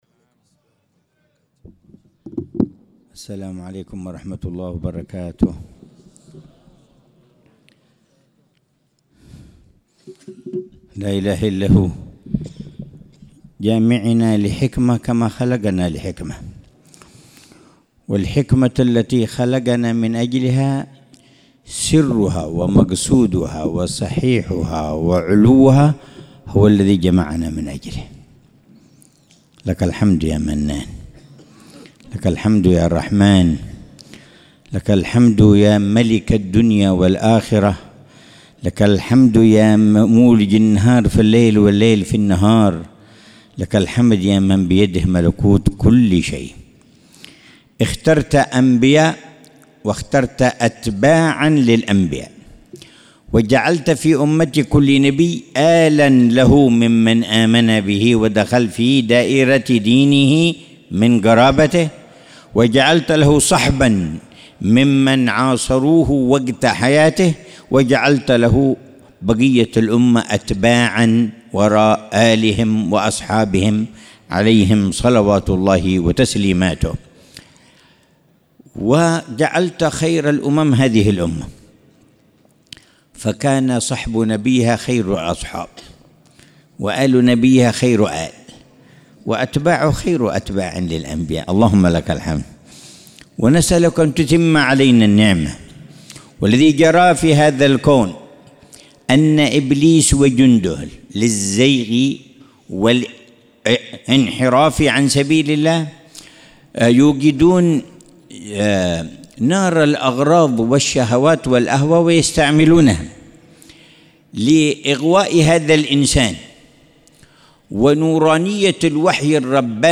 محاضرة العلامة الحبيب عمر بن محمد بن حفيظ في المجلس الخامس من مجالس الدعوة إلى الله في شعب النبي هود عليه السلام، ضمن محاور (تقوية الإيمان وتقويم السلوك) ، ليلة الأحد 10 شعبان 1446هـ بعنوان: